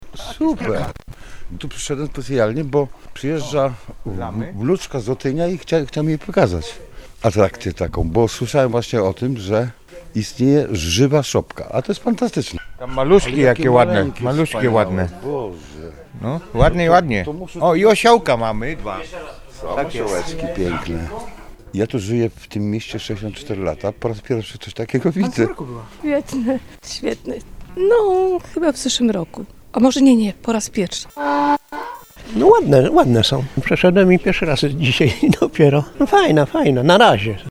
Od rana szopkę odwiedza sporo gości: